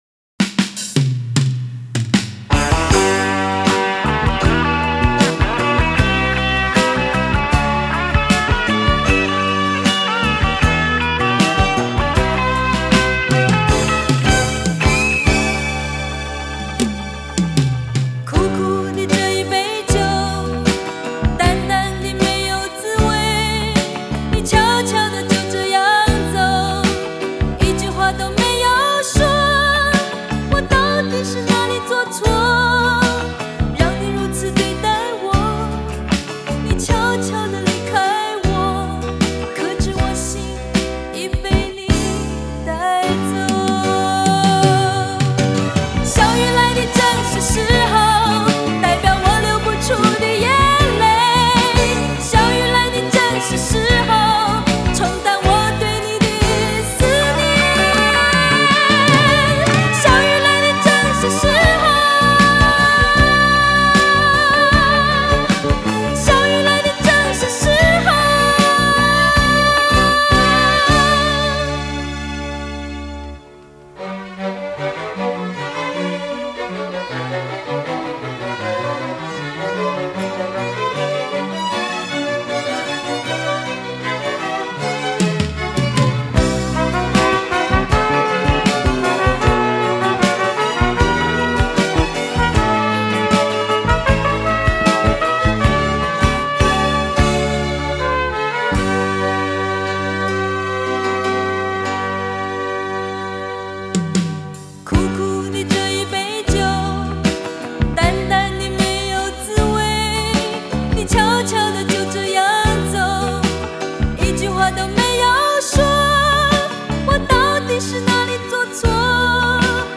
典型的七十年代末、八十年代初台湾流行音乐的传统手 笔